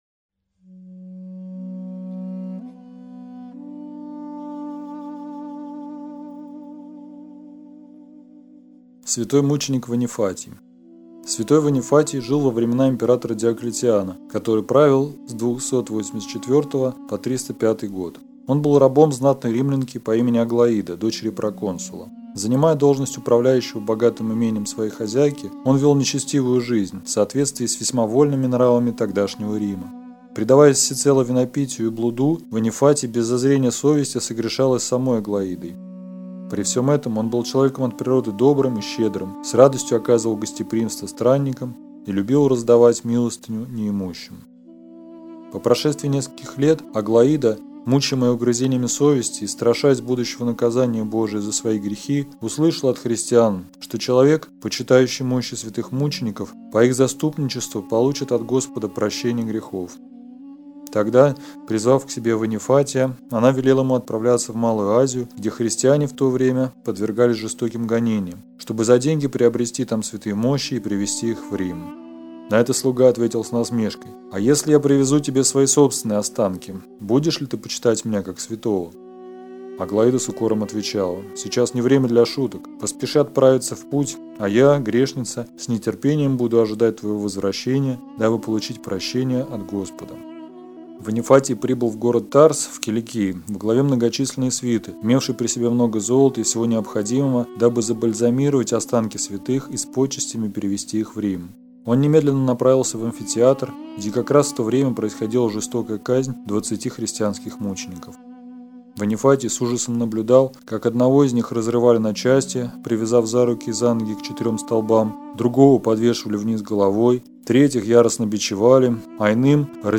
Читает иеромонах